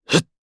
Clause_ice-Vox_Attack1_jp.wav